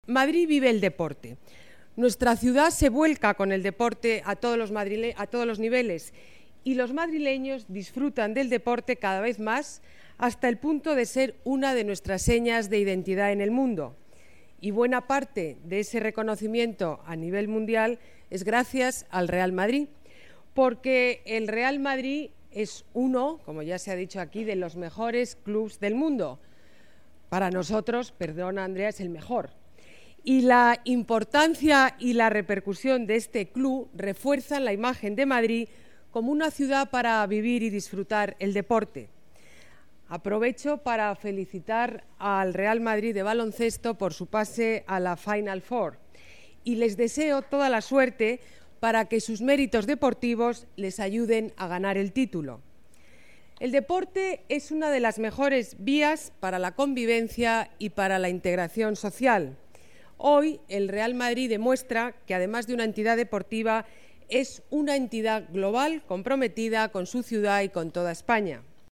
Más archivos multimedia Alcaldesa: Madrid es una ciudad que vive el deporte Vídeo presentación Classic Match Más documentos Intervención alcaldesa Madrid, Ana Botella, presentación Corazón Classic Match 2013